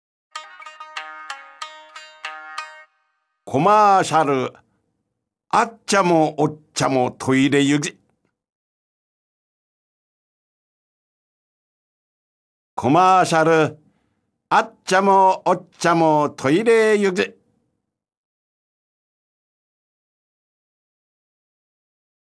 本場のお国言葉を読み上げる方言かるたシリーズ第二弾「津軽弁かるた」が新登場！